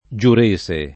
giurese [ J ur %S e ]